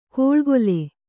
♪ kōḷgole